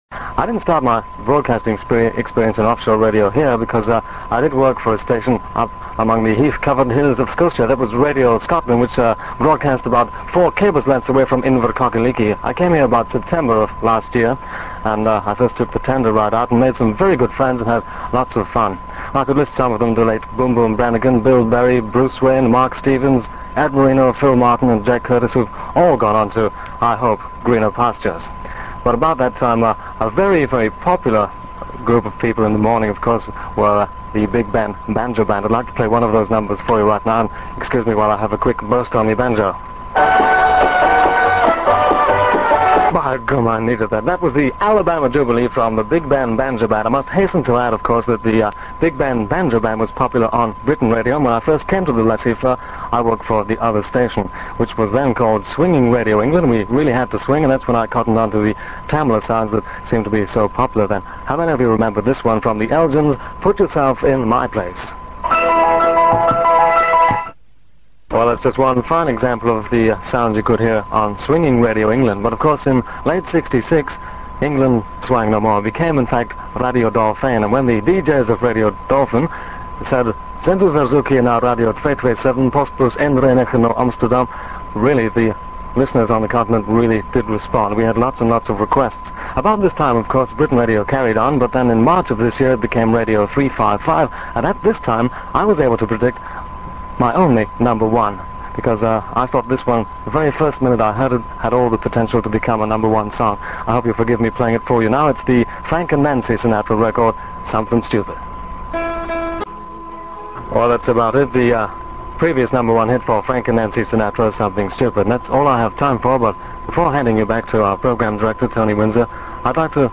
All the presenters talked about their careers, paid tribute to their colleagues, past and present, and said goodbye to the listeners.